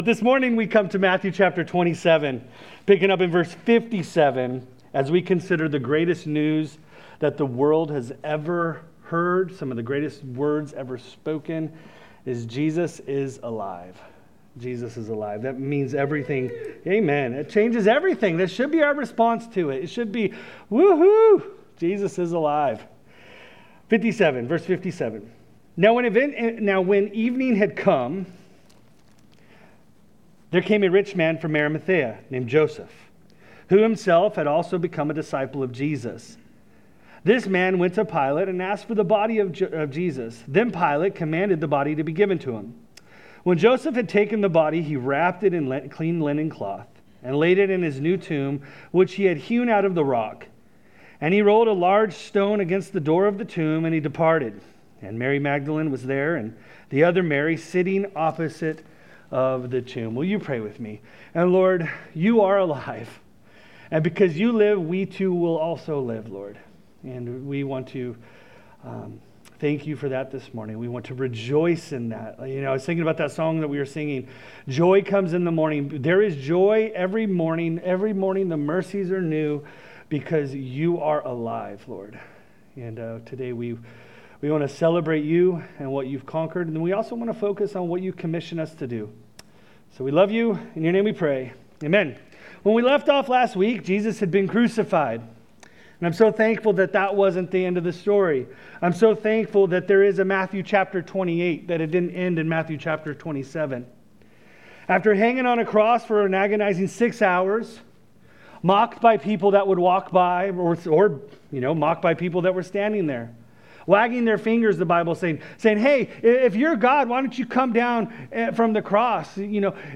Sermon Series – Calvary Chapel West Ashley